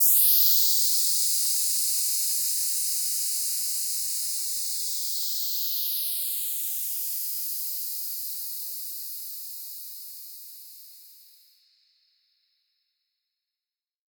Index of /musicradar/shimmer-and-sparkle-samples/Filtered Noise Hits
SaS_NoiseFilterC-03.wav